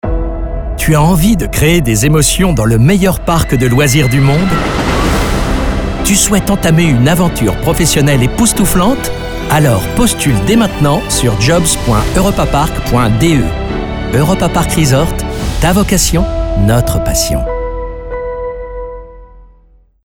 Funkspot – französische Version FR